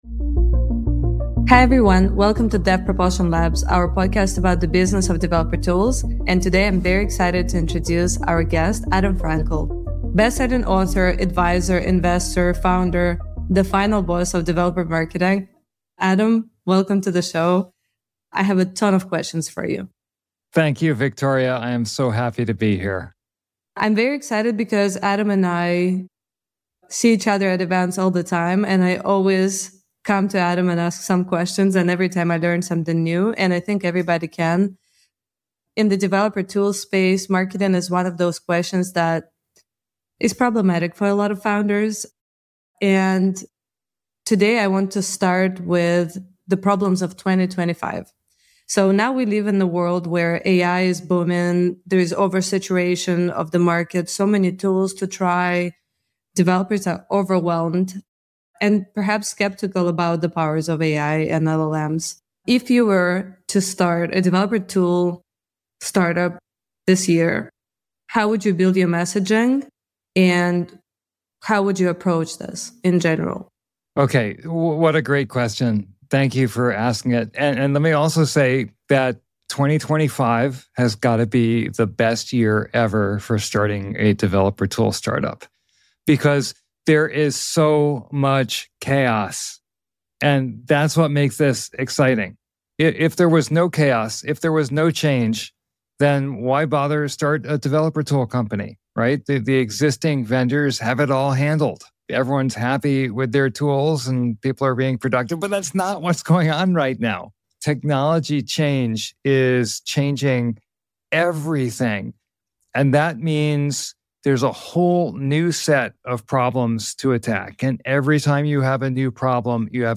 Dev Propulsion Labs is a podcast about building successful developer tool companies. Hosts from the Evil Martians team interview prominent dev tools founders with the goal of sharing knowledge in the maturing developer tool and commercial open source industry.